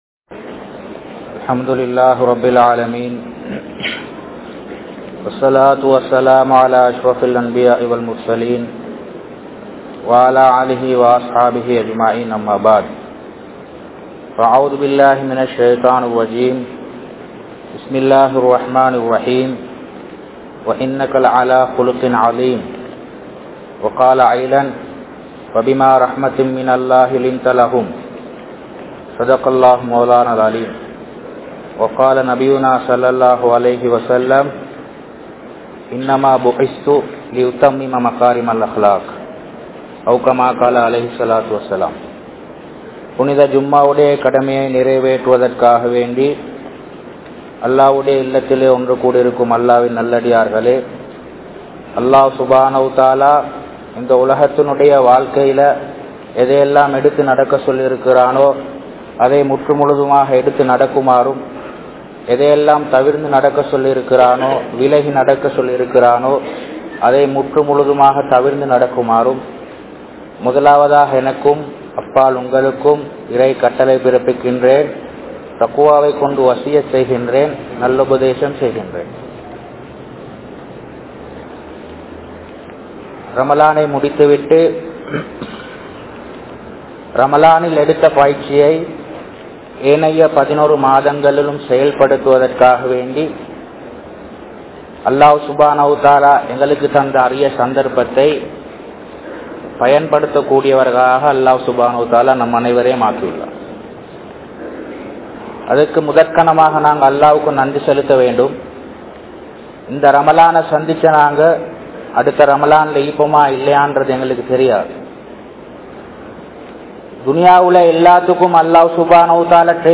Irakkamillaatha Manitharhal (இரக்கமில்லாத மனிதர்கள்) | Audio Bayans | All Ceylon Muslim Youth Community | Addalaichenai
Nawalapitiya, Muhiyadeen Jumuah Masjidd